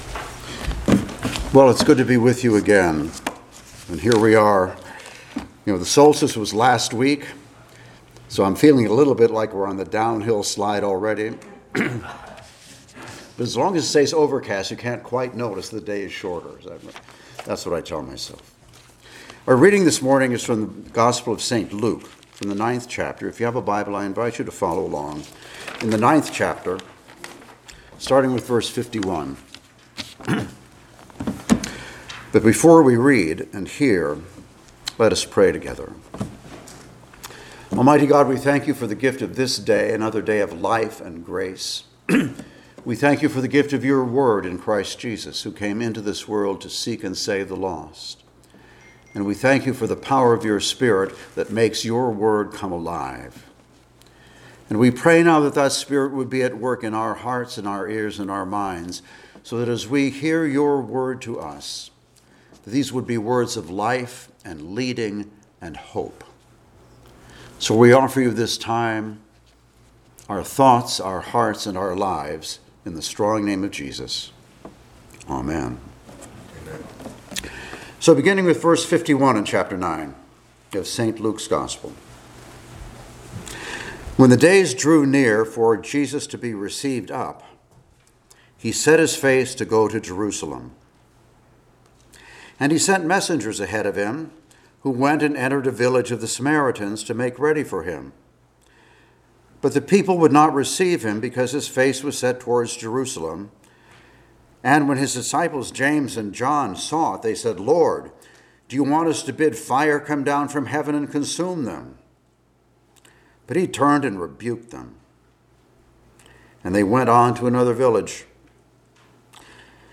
Passage: Luke 9:51-62 Service Type: Sunday Morning Worship